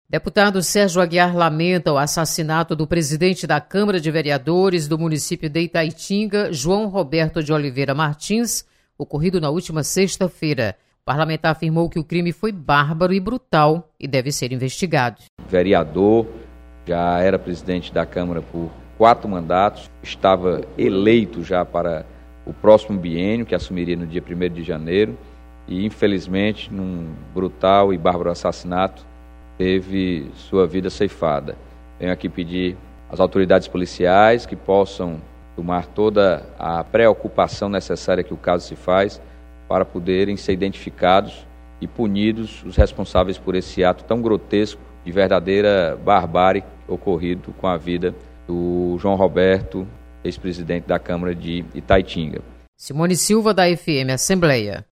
Deputado Sérgio Aguiar lamenta assassinato de vereador. Repórter